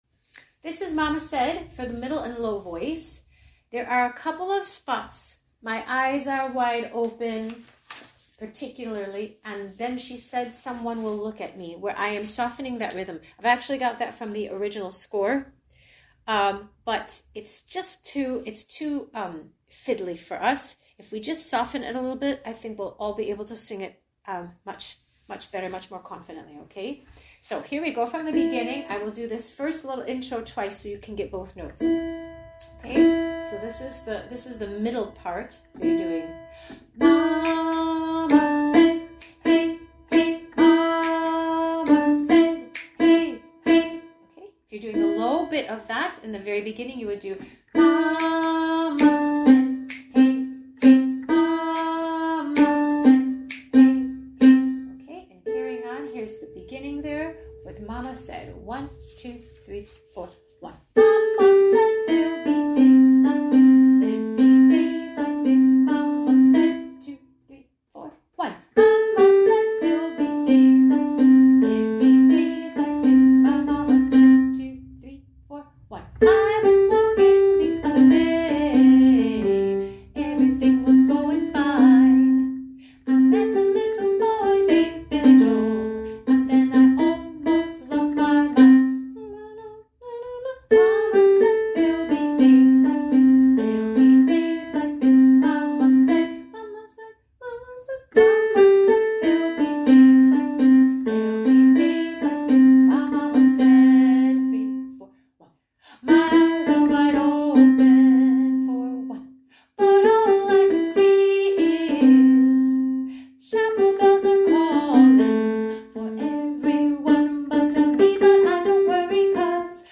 Sing Along Tracks (mp3):High VoiceMiddle and Low Voice
Middle and Low Voice